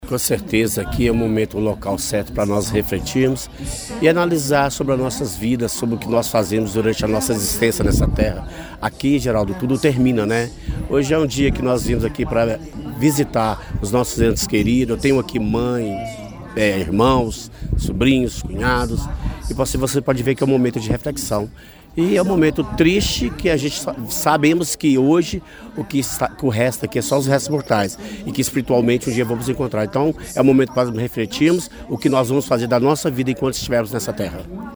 O Dia de Finados propõe a todos uma reflexão sobre o sentido da vida, também de fé e recordação das boas lembranças deixadas por amigos e parentes que já partiram, como disse ao Portal GRNEWS, um dos visitantes, o presidente da Mesa Diretora da Câmara Municipal, Márcio Lara (PSD):